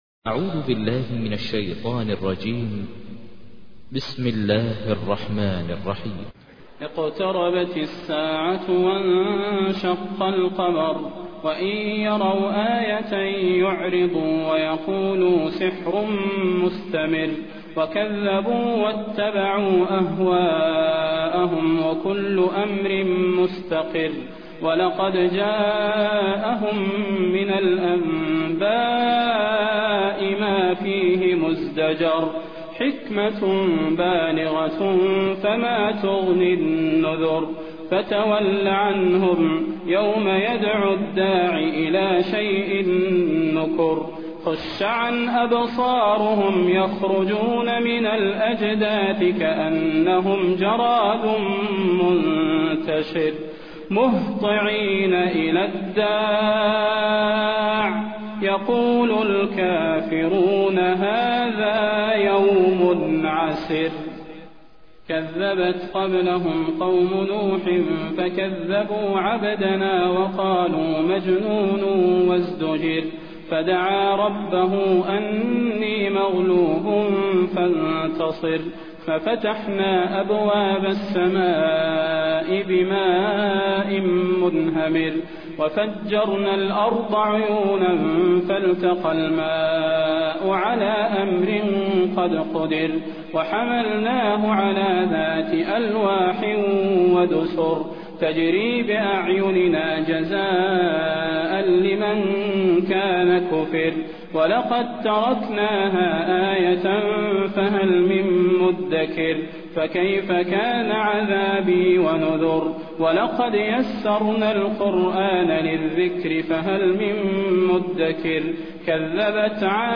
تحميل : 54. سورة القمر / القارئ ماهر المعيقلي / القرآن الكريم / موقع يا حسين